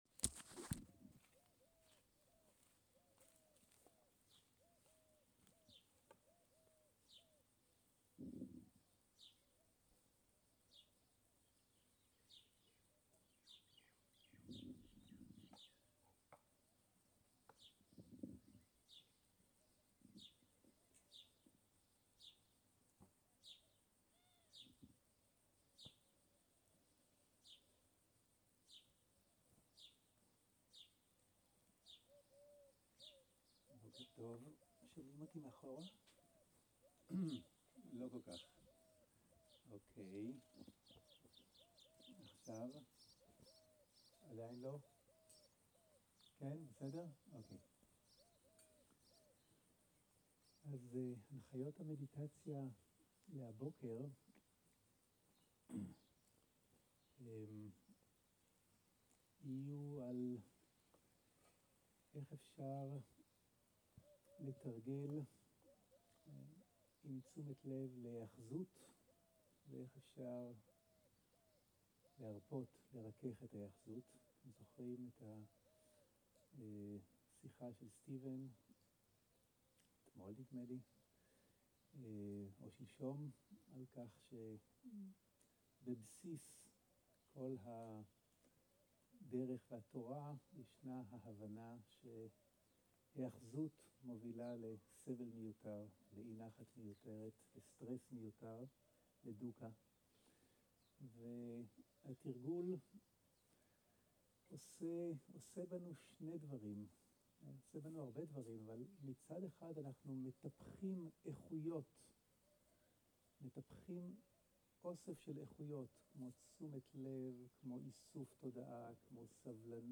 סוג ההקלטה: שיחת הנחיות למדיטציה
איכות ההקלטה: איכות גבוהה